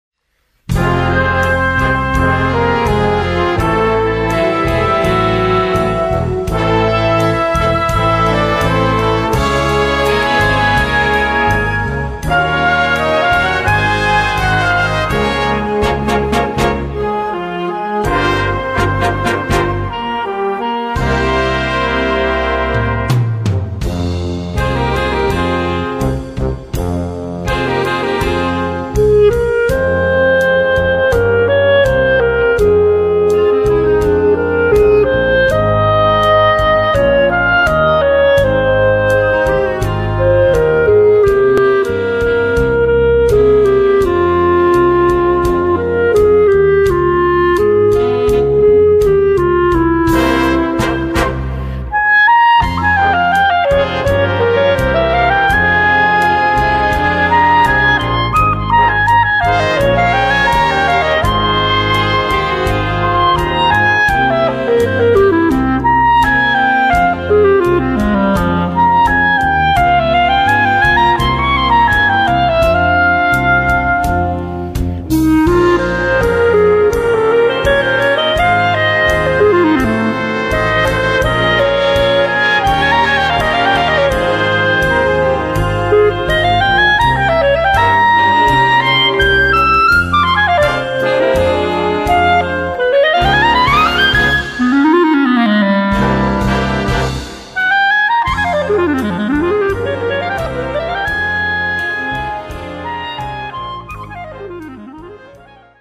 Voicing: Eb Bass and Brass Band